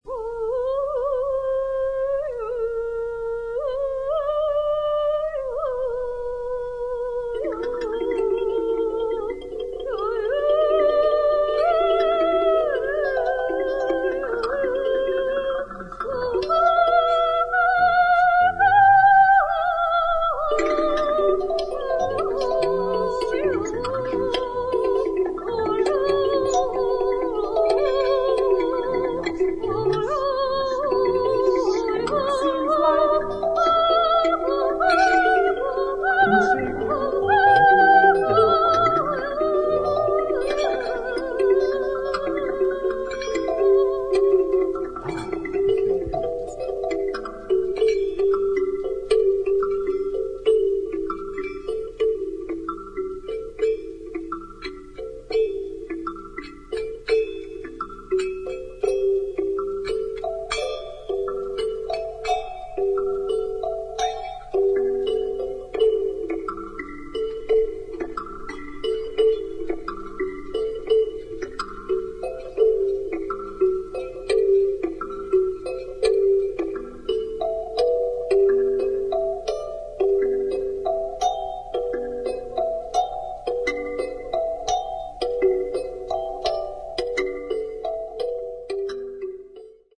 カリンバの展開が素晴らしい